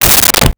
Car Door Close 06
Car Door Close 06.wav